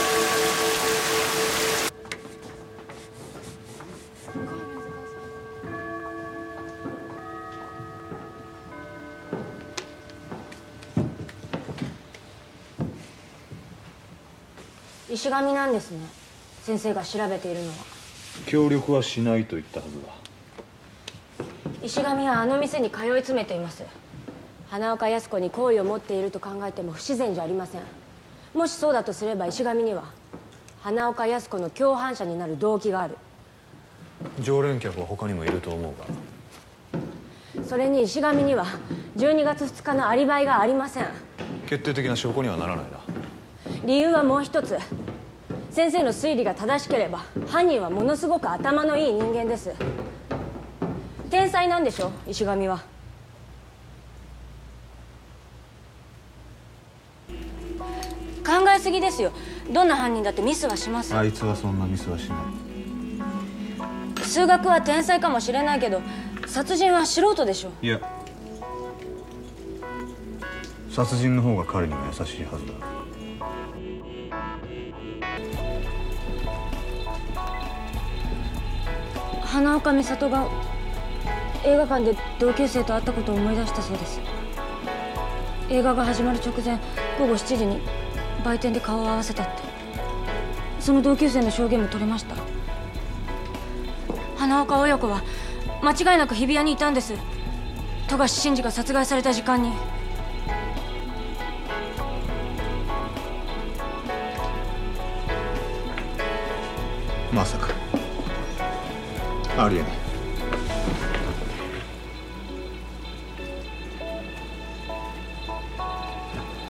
IMDb link The fifth scene in the classroom after a lecture.